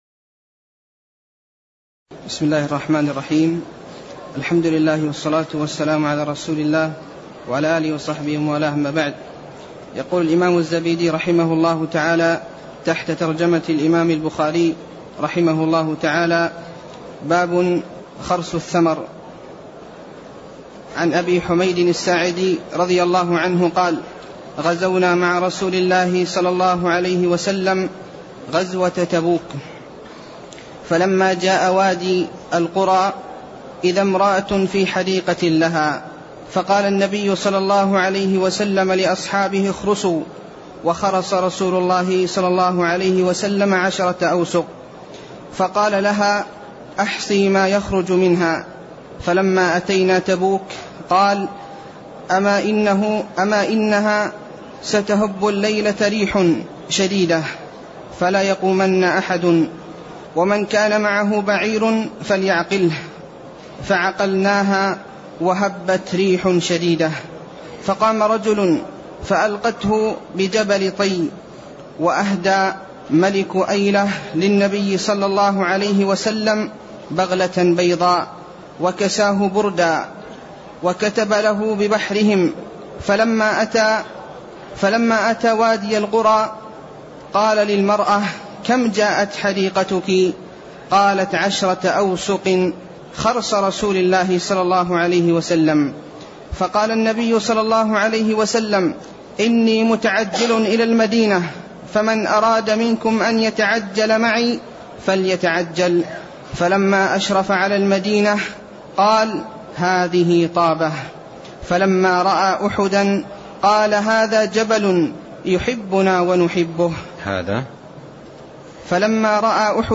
تاريخ النشر ٢ رجب ١٤٣٤ هـ المكان: المسجد النبوي الشيخ: فضيلة الشيخ عبد الرزاق بن عبد المحسن البدر فضيلة الشيخ عبد الرزاق بن عبد المحسن البدر باب خرص الثمر (08)